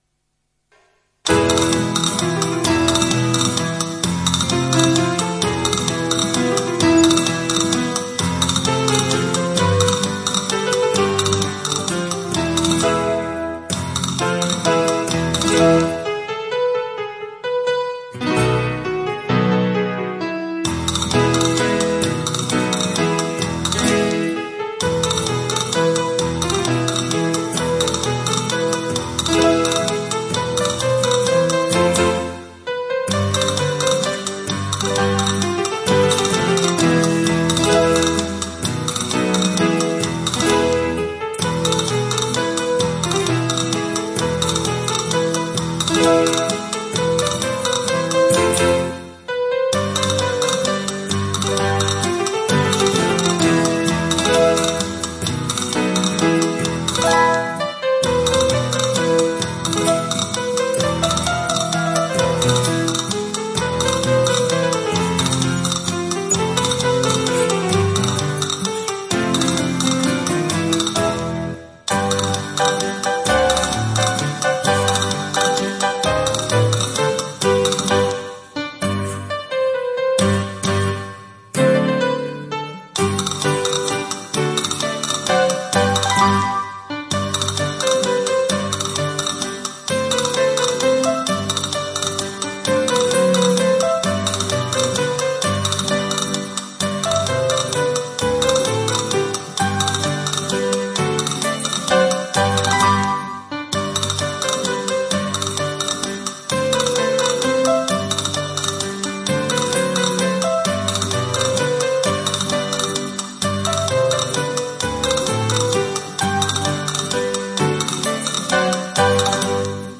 Sevillanas instrumentales, “como las de antes”, para celebrar la fiesta “desde casa” ESCUCHAR LA NOTICIA EN ARCHIVOS ADJUNTOS